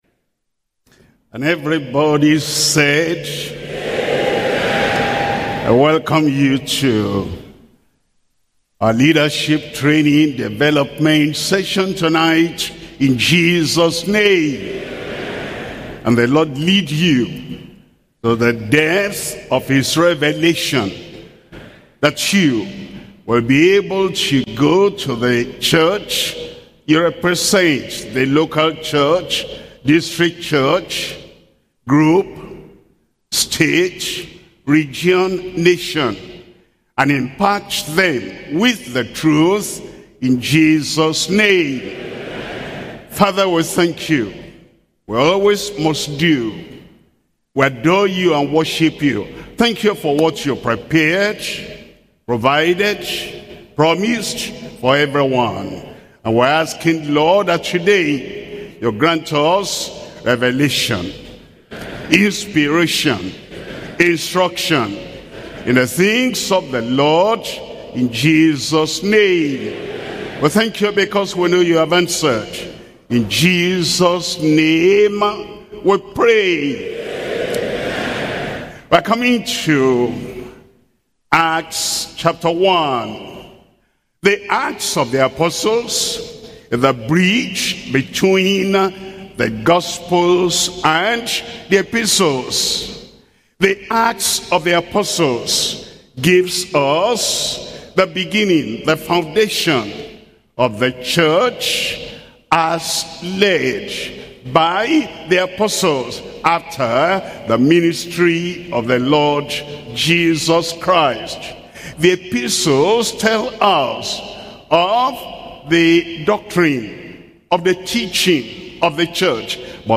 Sermons - Deeper Christian Life Ministry